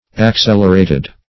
Accelerated - definition of Accelerated - synonyms, pronunciation, spelling from Free Dictionary